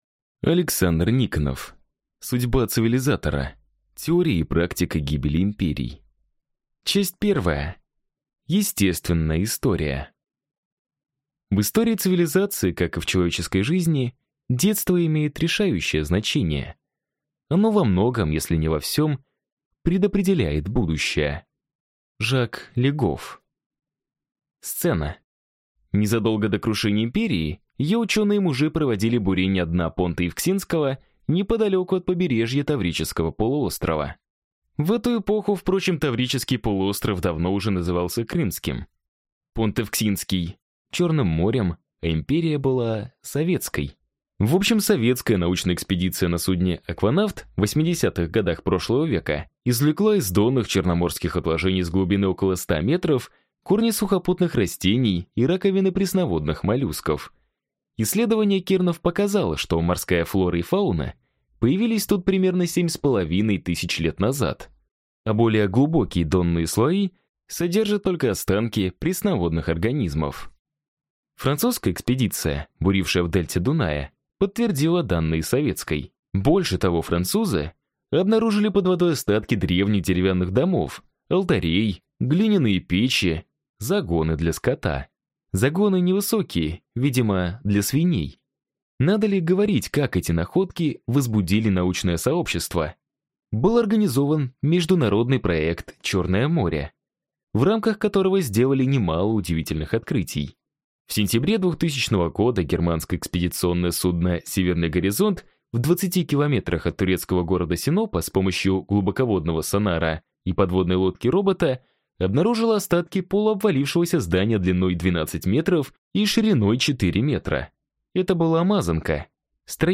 Аудиокнига Судьба цивилизатора. Теория и практика гибели империй | Библиотека аудиокниг